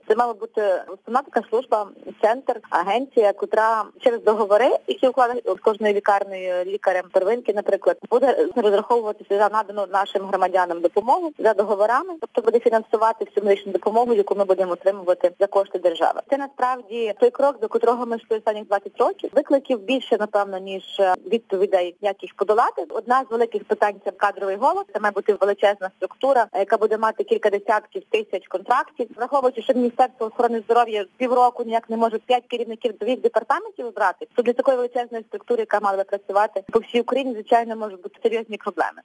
Про завдання відомства в ефірі Українського радіо розповіла експерт з медичної реформи